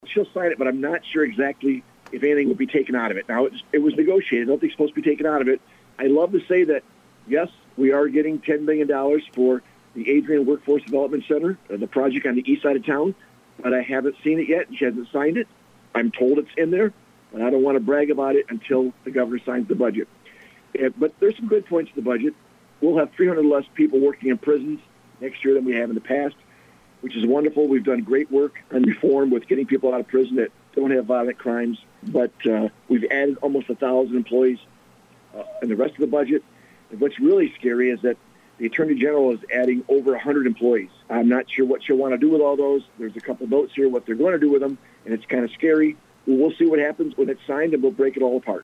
Adrian, MI – The Michigan House and Senate have approved the State budget for the next fiscal year, and the Senator for the majority of Lenawee County, Joe Bellino, gave his reaction on a recent 7:40am break.